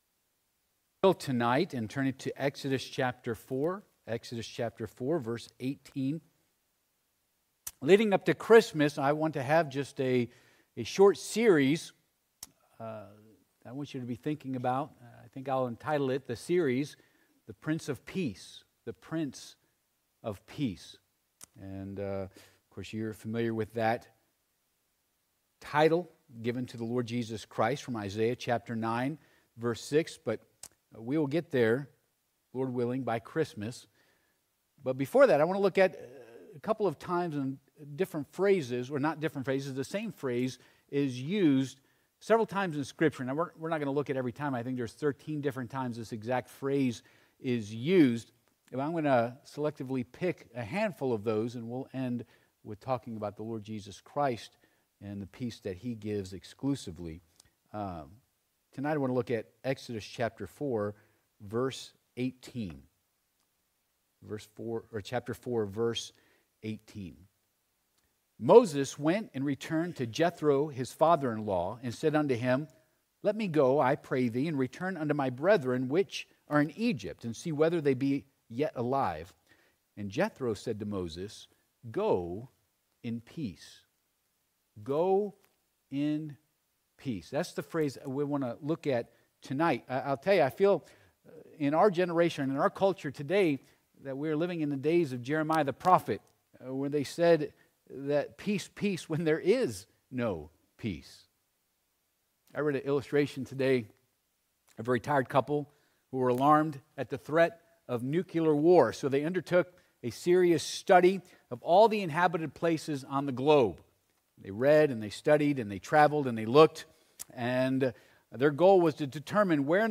Ex. 4:18 Service Type: Midweek Service « Are You a Wet Blanket or a Torch for God?